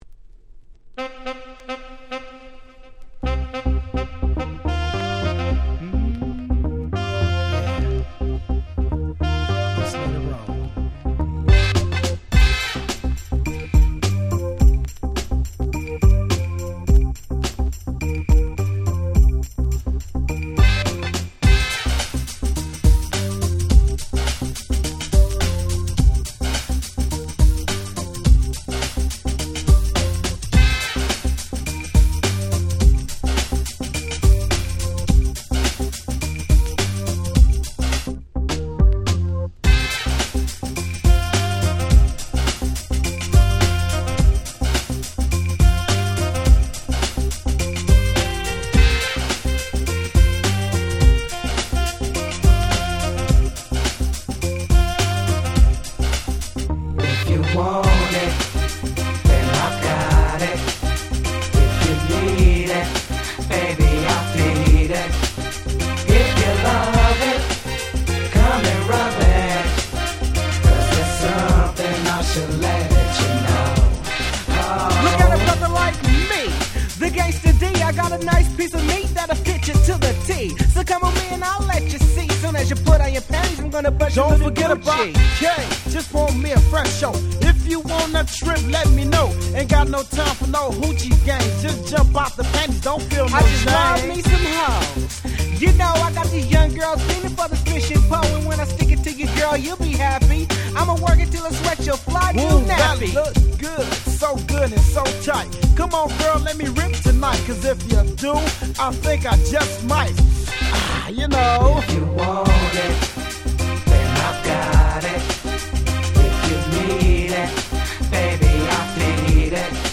92' Smash Hit West Coast Hip Hop !!